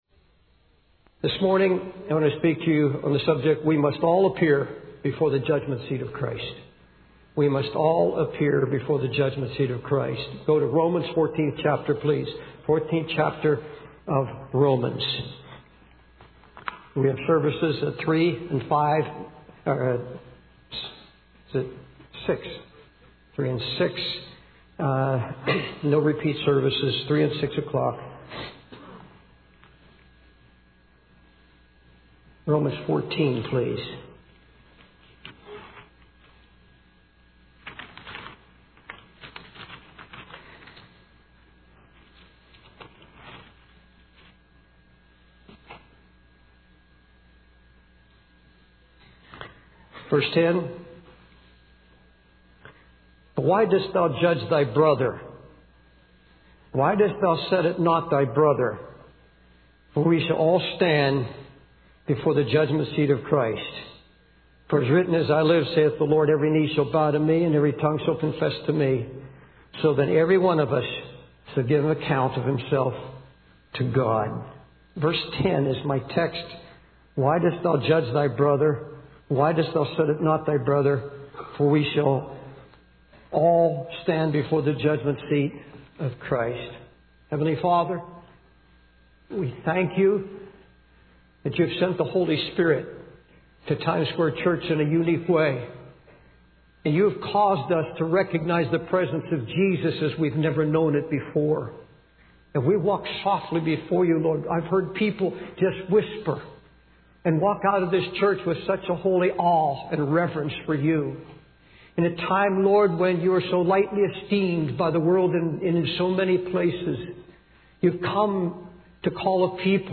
In this sermon, the preacher emphasizes the importance of appearing before the Judgment Seat of Christ. He references Romans 14:10, which states that everyone will give an account of themselves to God.